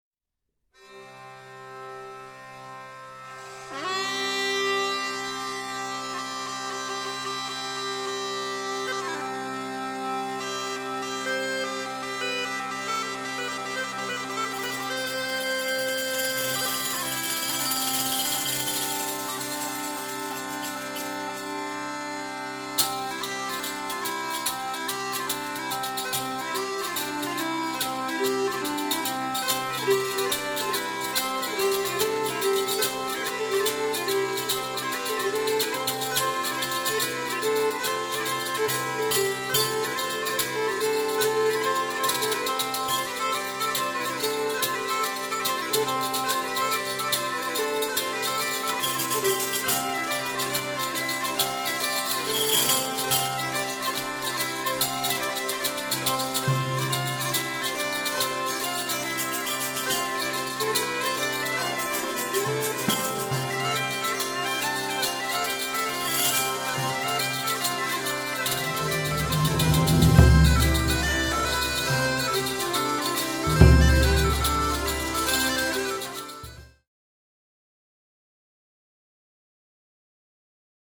gespielt auf Instrumenten der Manufaktur